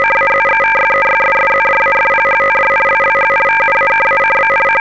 Audio: To hear the sound of a SZ40/42 transmission